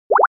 error_touch.mp3